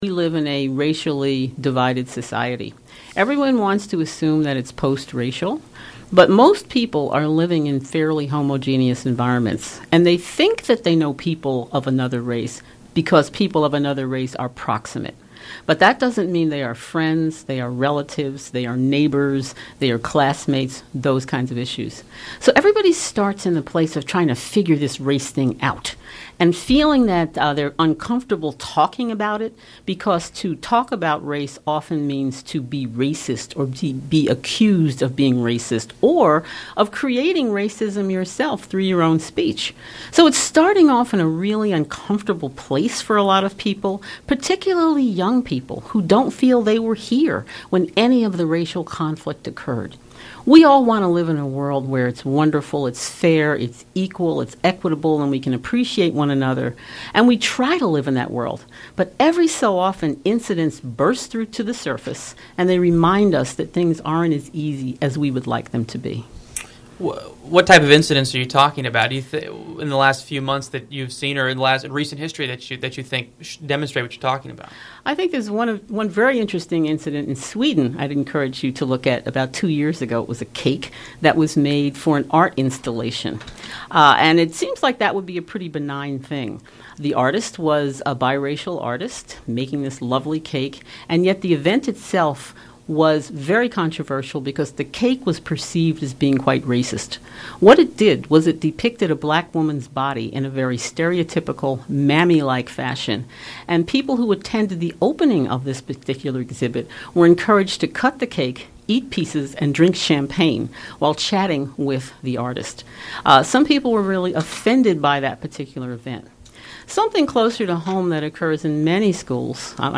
This interview was originally broadcast on Capstone News Now on WVUA-FM in Tuscaloosa, AL on Oct 22, 2014.